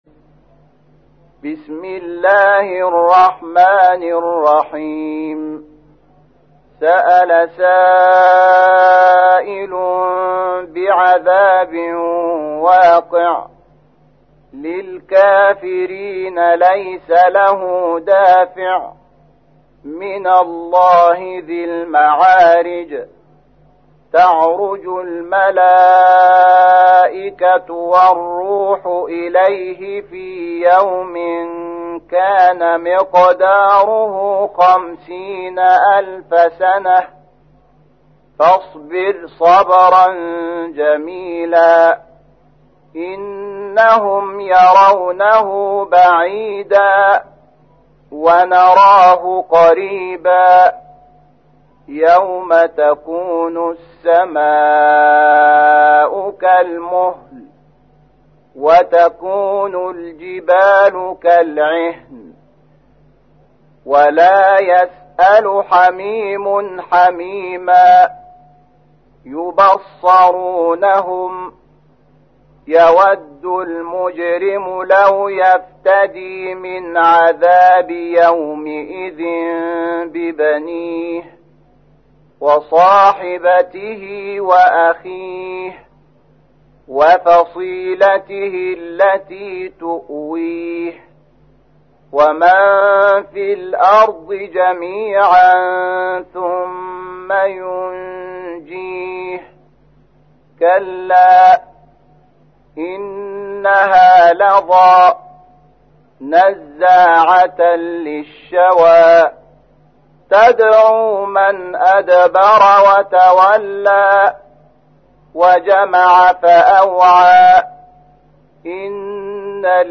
تحميل : 70. سورة المعارج / القارئ شحات محمد انور / القرآن الكريم / موقع يا حسين